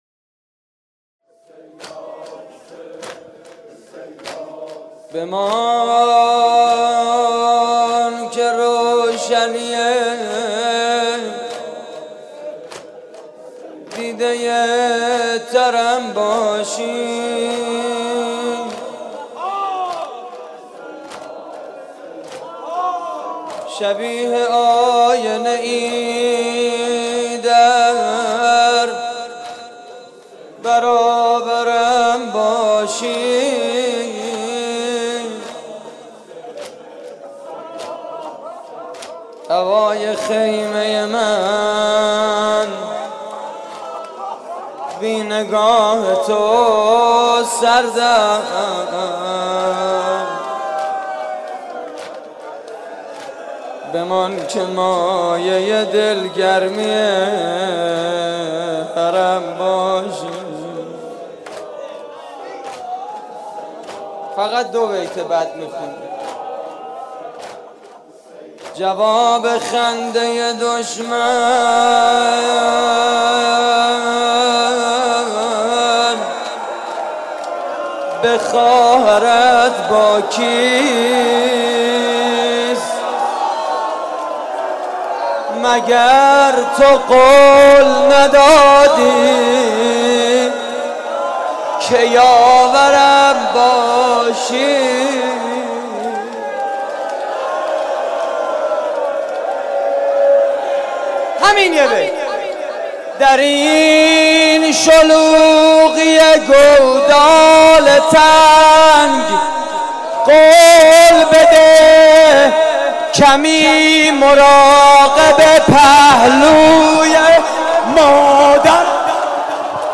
مراسم عزاداری شب عاشورای حسینی (محرم 1433) / هیئت کریم آل طاها (ع) – نازی‌آباد؛